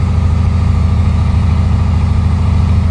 sound / acf_engines / w16small.wav